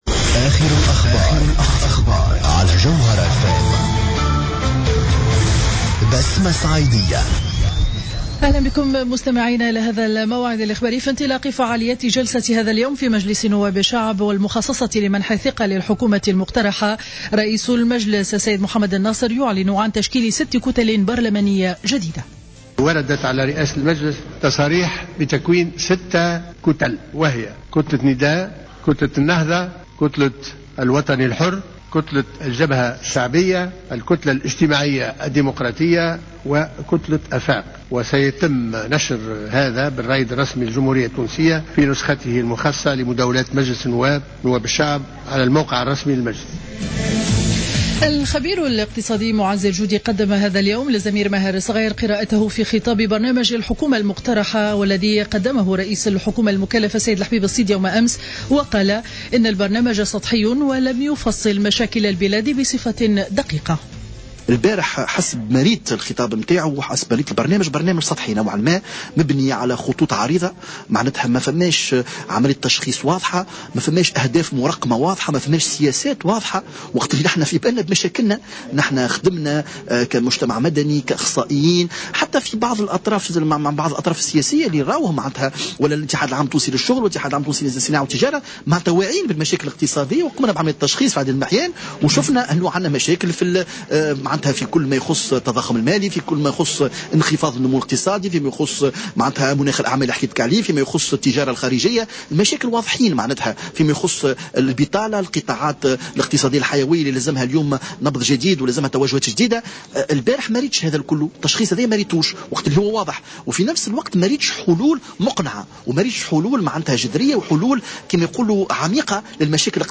نشرة أخبار منتصف النهار ليوم الخميس 05-02-15